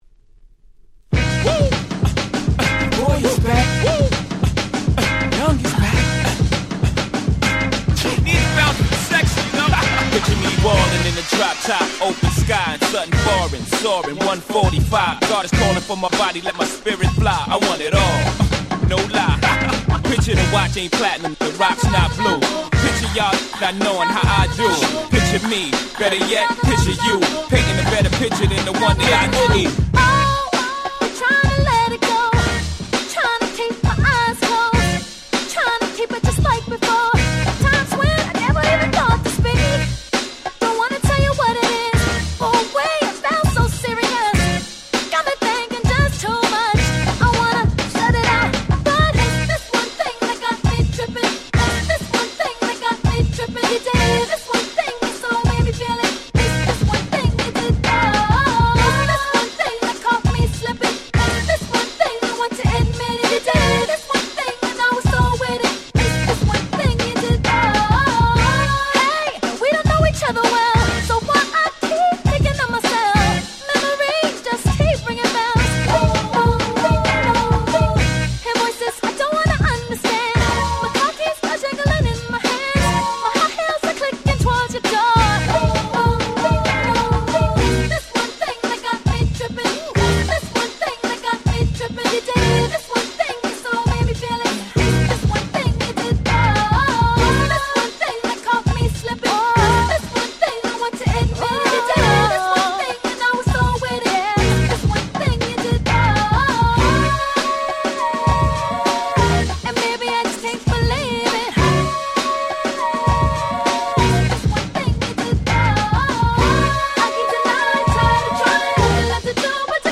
05' Super Hit R&B !!